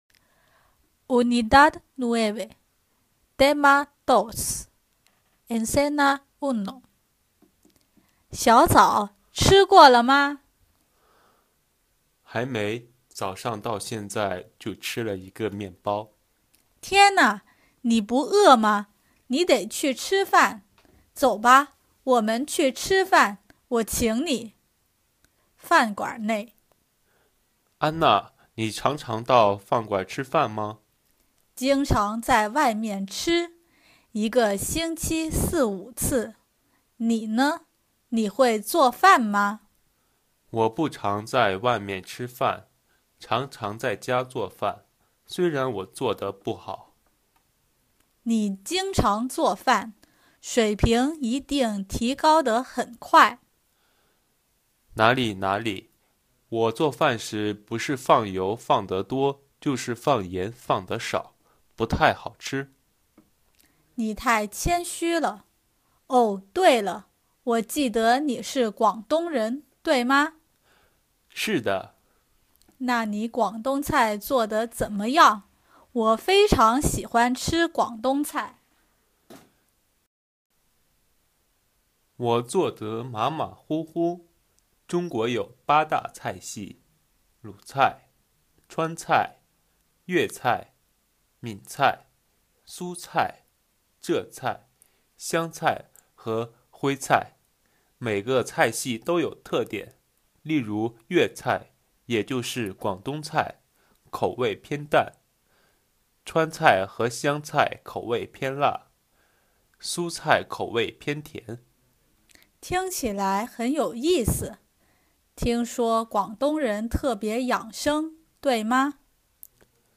Diálogo